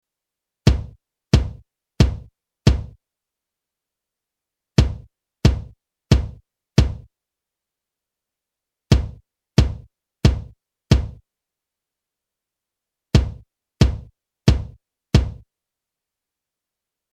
• Kick Drum
Tech note: I will use excessive boosting (WAY more than “proper” EQing) for the sole purpose of making those sonic characteristics stand out!
5 (500Hz)
fk5_Kick_Drum_500Hz_boost.mp3